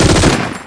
Machine Gun Sound #2
The first MG is good too but the second sounds more like some rapid consecutive mini explosions.
i'm using Portable GoldWave, MG Sound was recorded from Some Movies, but i cant' remember what movie it is, cuz that MG Sound was made long time ago and i've just edit them yesterday.